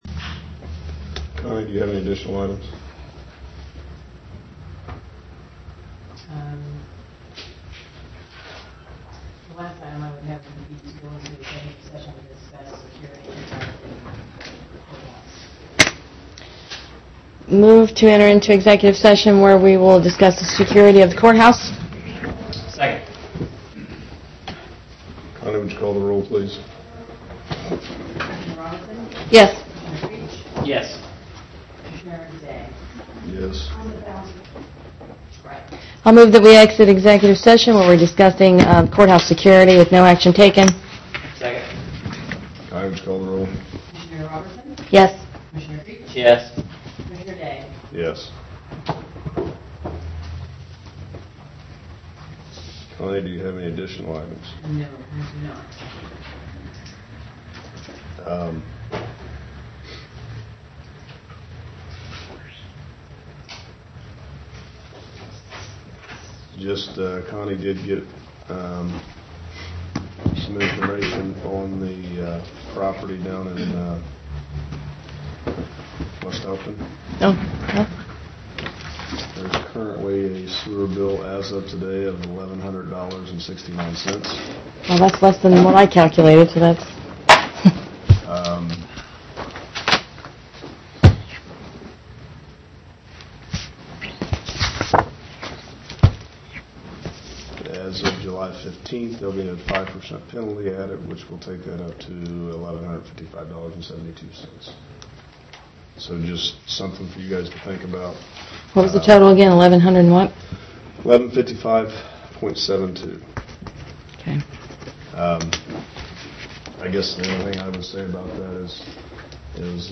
First commission audio review in a couple months, the audio level is poor when the meetings move to the conference table. I have it cranked up and hope it helps.